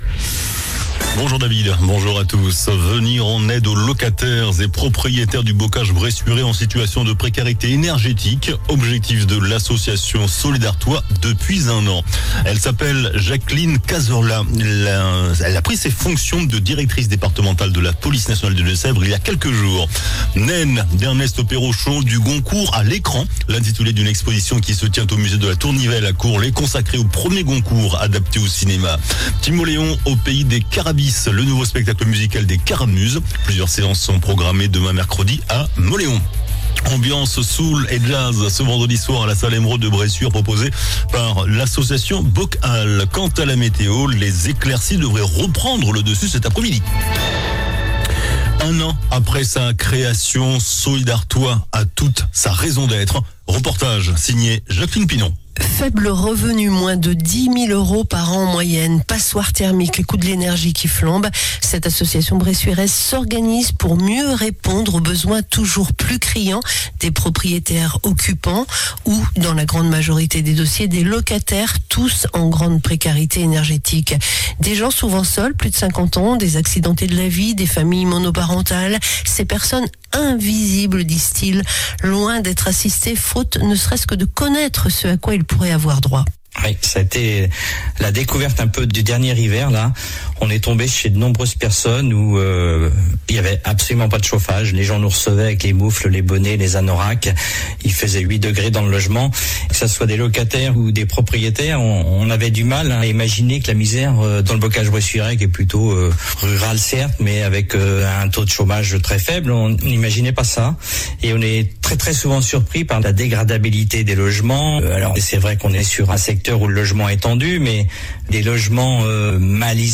JOURNAL DU MARDI 22 OCTOBRE ( MIDI )